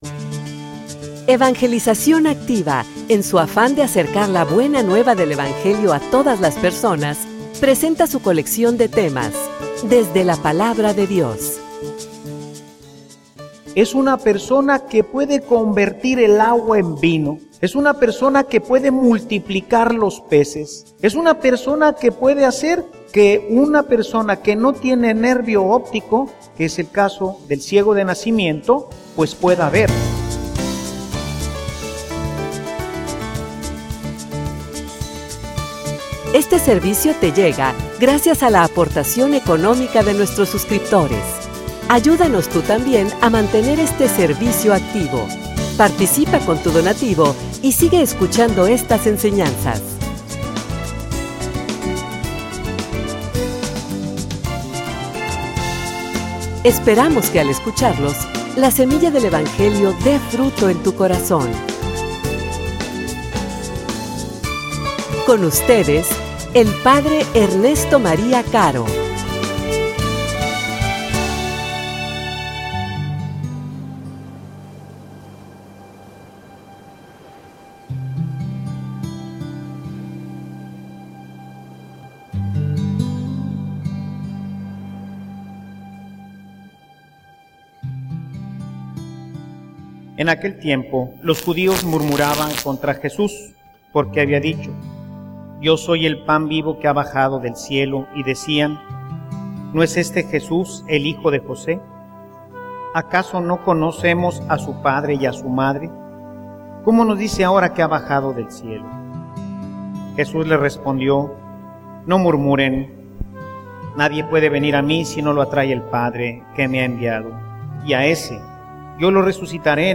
homilia_Realmente_tienes_fe.mp3